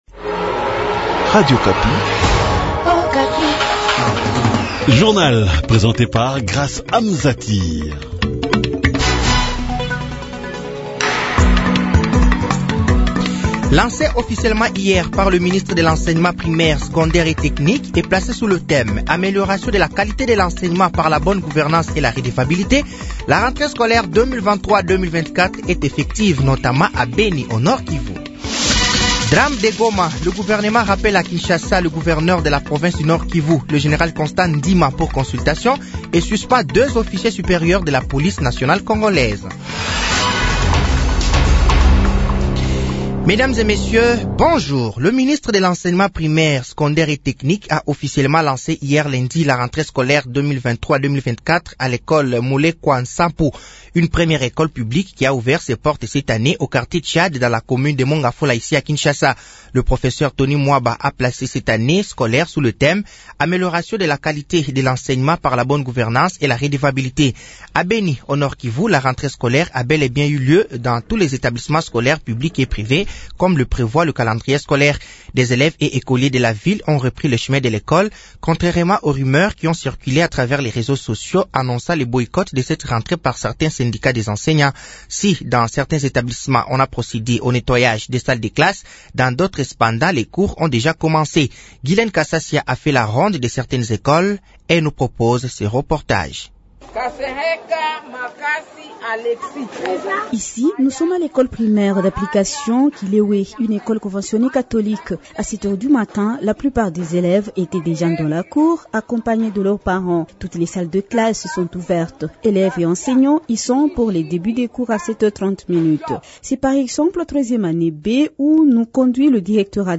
Journal français de 7h de ce mardi 05 septembre 2023